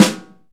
Index of /90_sSampleCDs/Roland LCDP03 Orchestral Perc/SNR_Orch Snares/SNR_Dry Snares
SNR SNORCH 4.wav